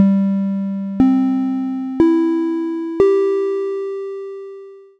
4-tone chime UP
bell chime ding microphone pa ping ring sound effect free sound royalty free Sound Effects